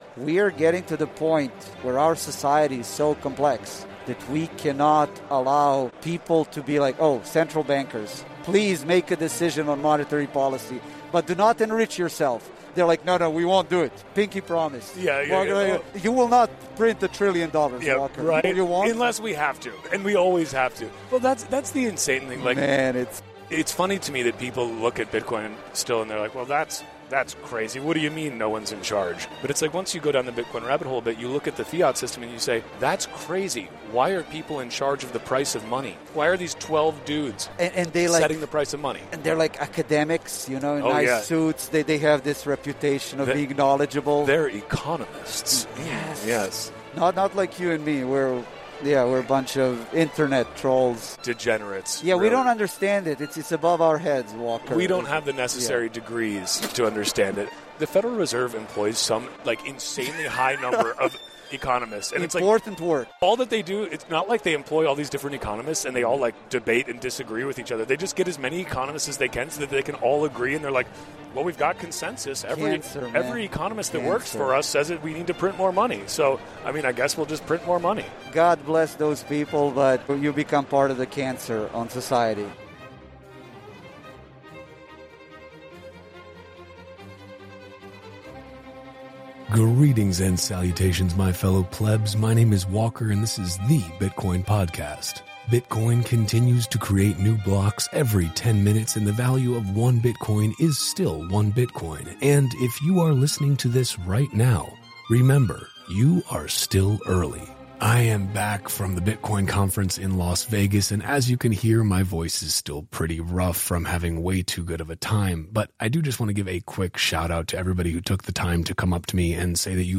VEGAS 2025